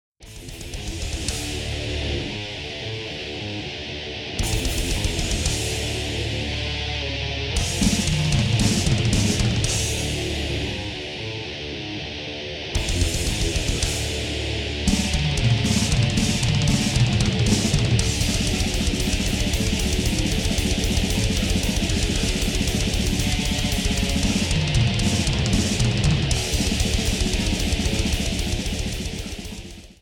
A play-along track in the style of rock, metal.
(With Drums)